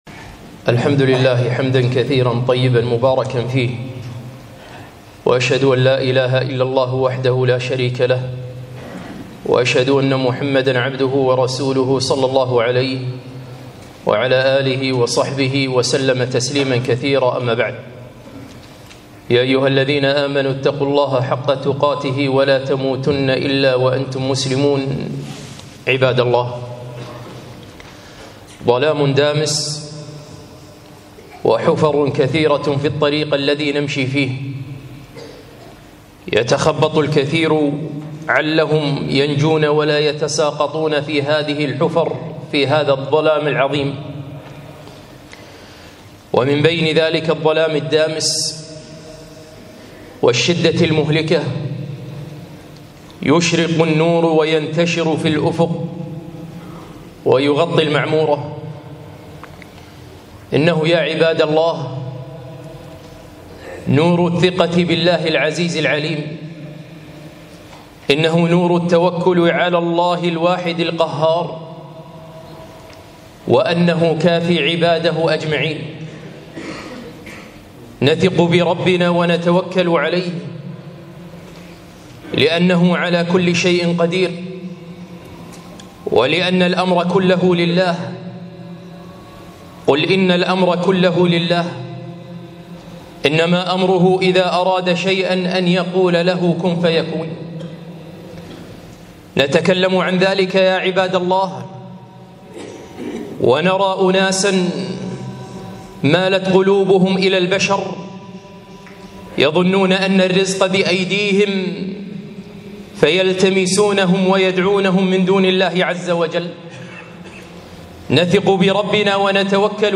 خطبة - الثقة بالله عز وجل